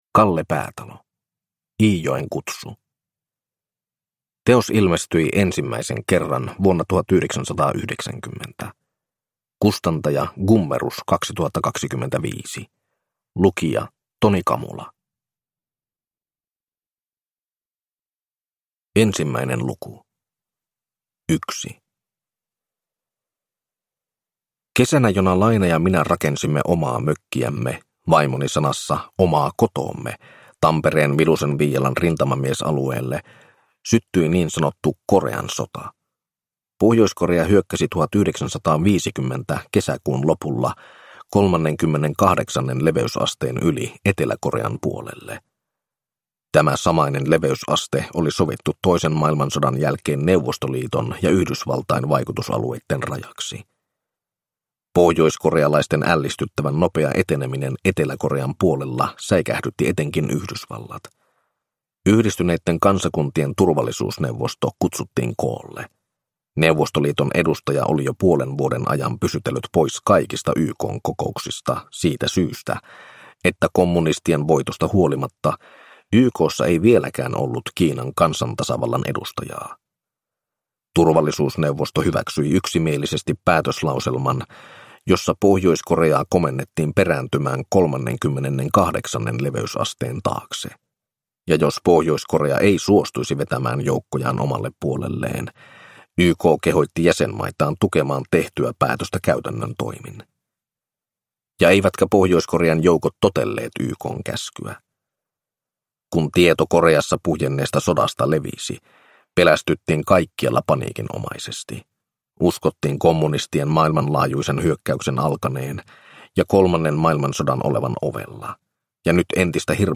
Iijoen kutsu (ljudbok) av Kalle Päätalo